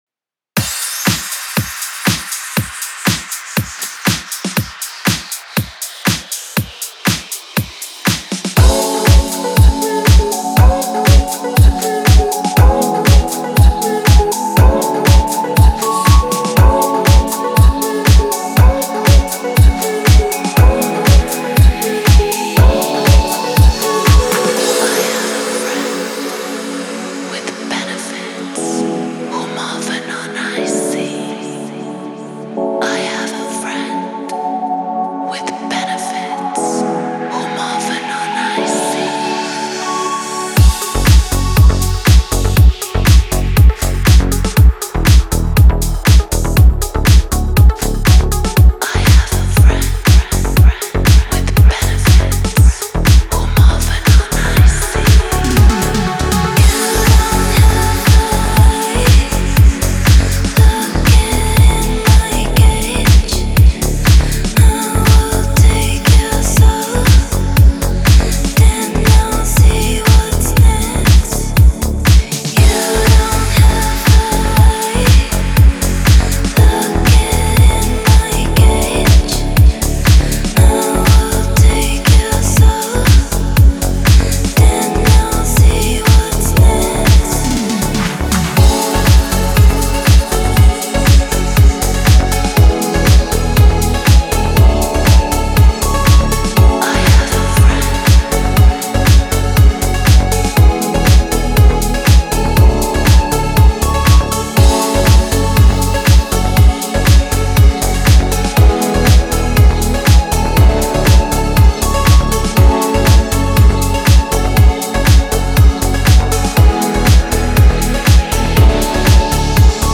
это захватывающий трек в жанре deep house